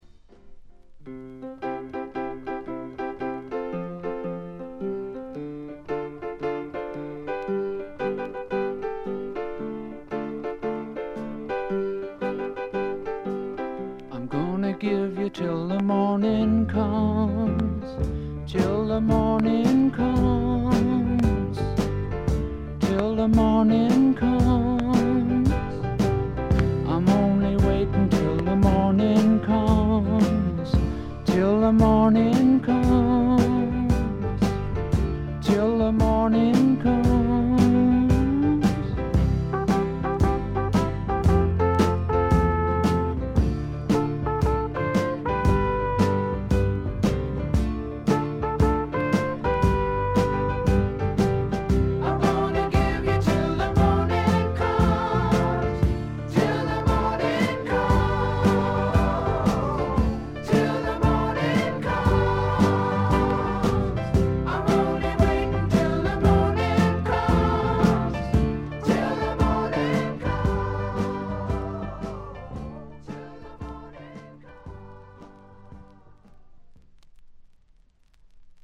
A5の歌が始まる辺りで軽い周回ノイズ6回ほど。
試聴曲は現品からの取り込み音源です。